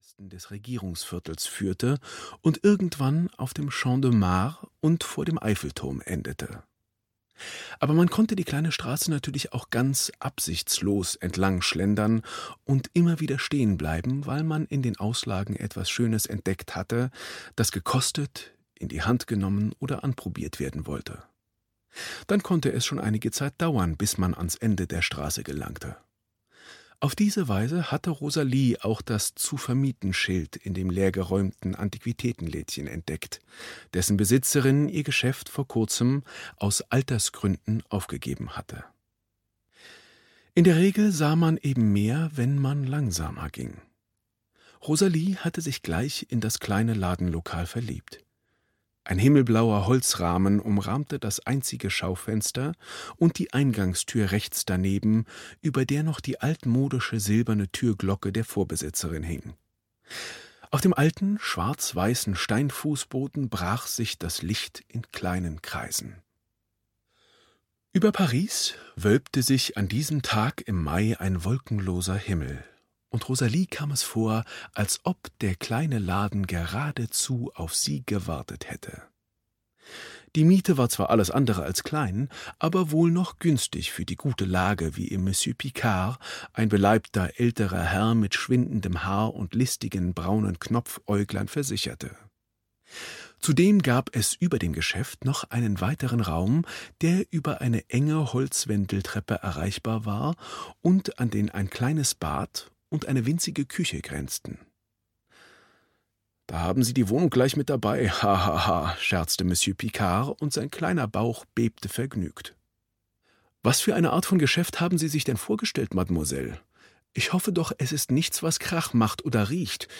Paris ist immer eine gute Idee - Nicolas Barreau - Hörbuch